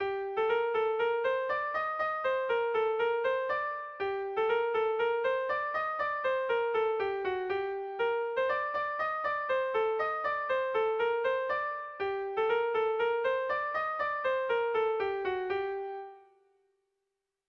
Sentimenduzkoa
Zortziko ertaina (hg) / Lau puntuko ertaina (ip)
A1A2BA2